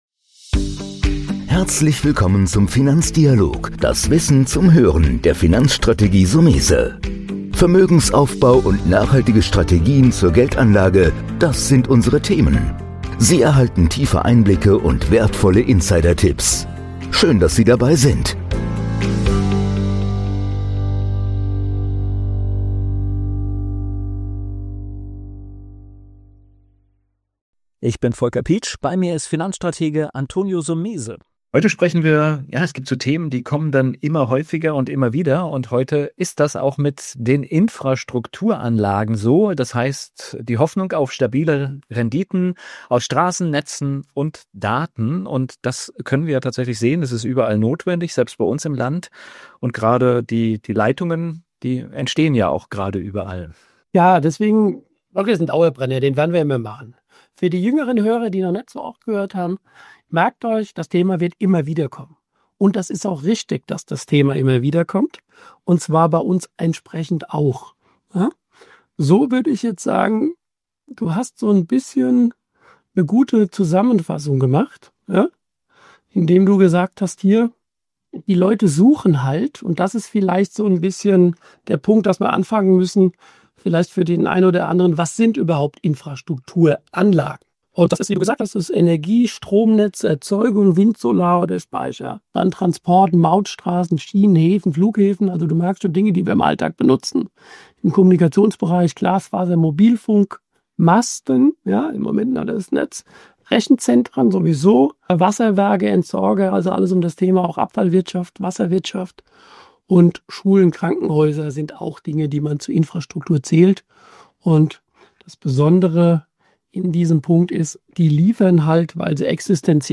Im Dialog mit unseren Gästen öffnen wir das Fenster zu Wirtschaft, Kapitalmarkt und Finanzwelt. Wir geben tiefe Einblicke und wertvolle Insidertipps.